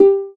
TouchpadSound_confirm.wav